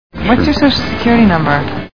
Iron Man Movie Sound Bites